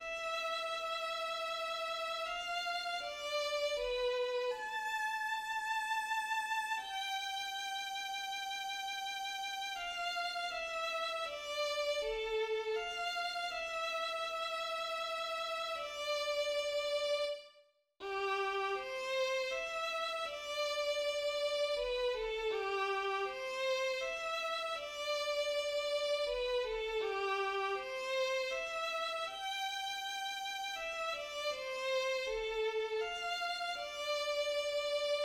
2. Andante en ut majeur (environ 8 minutes)
La partition est écrite pour violon solo et un orchestre symphonique classique, composé de deux flûtes, deux hautbois, deux clarinettes, deux bassons, deux cors, deux trompettes, des timbales et des cordes (deux pupitres de violons, altos, violoncelles et contrebasses)[2].
Thème du deuxième mouvement :
Le deuxième mouvement, un andante de coupe tripartite, est une typique « romance sans paroles ».